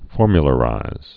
(fôrmyə-lə-rīz)